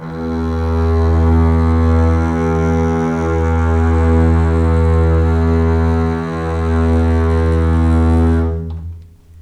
E2 LEG MF  R.wav